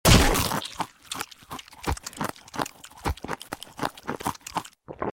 PLAY om nom nom roblox sound
bite_C8hZepz.mp3